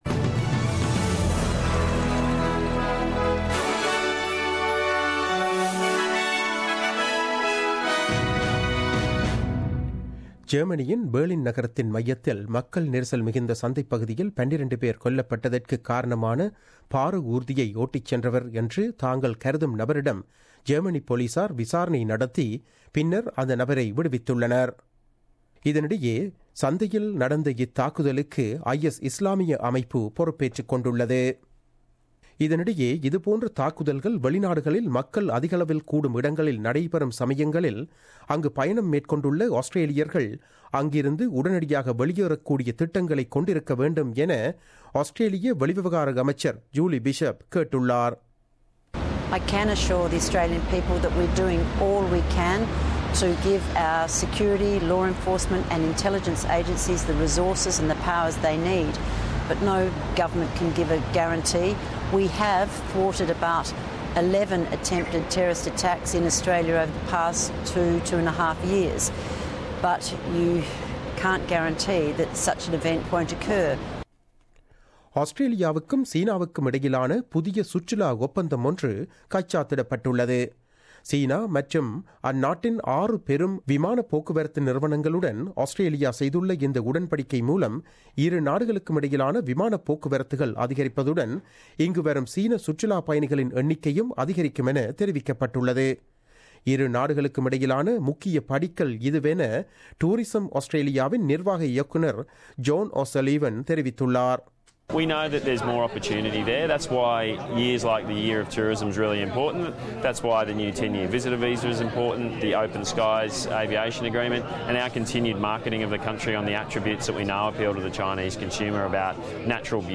The news bulletin aired on 21 December 2016 at 8pm.